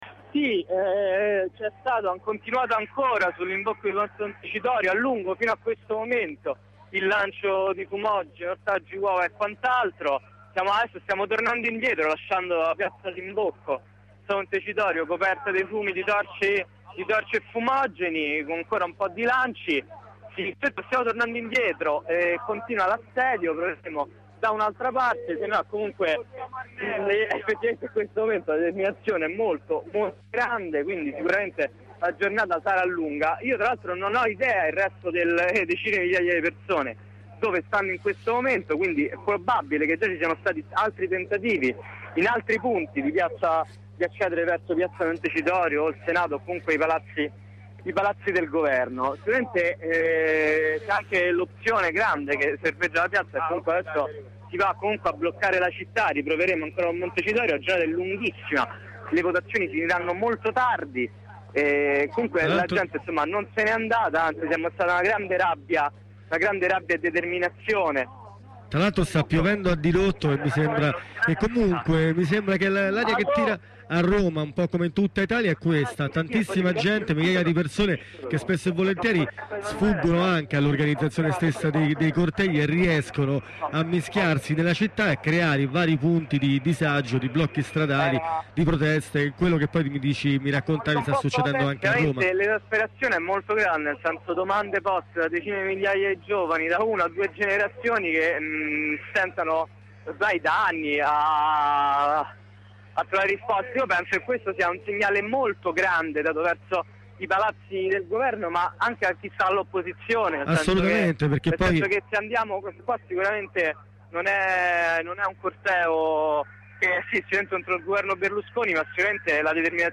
Ascolta le corrispondenze con gli studenti di Roma, Bologna e Pisa.